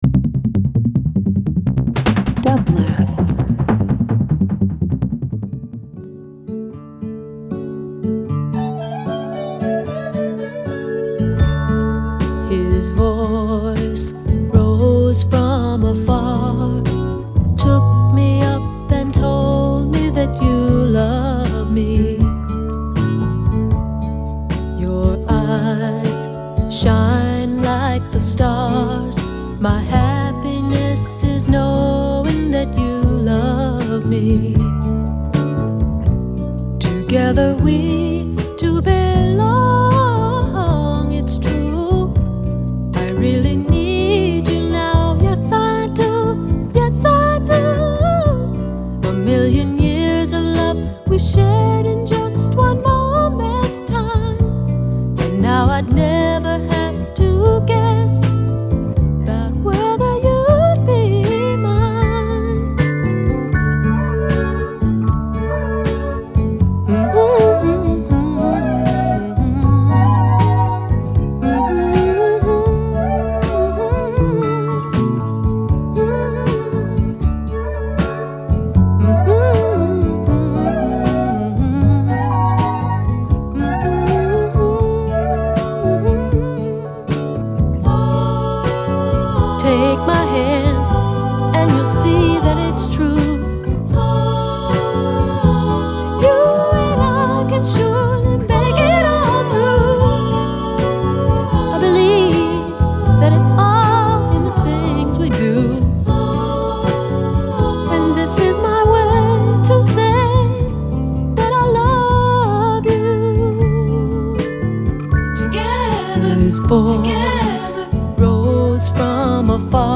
Alternative Folk Post Rock Soul